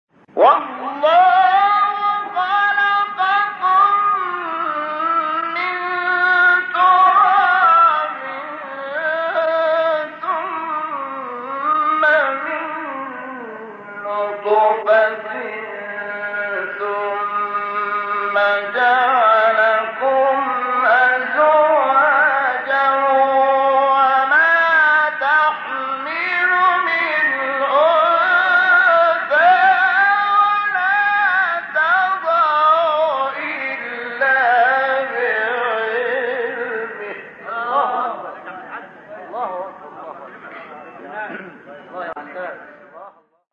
سوره : فاطر آیه: 11 استاد : شحات محمد انور مقام : بیات قبلی بعدی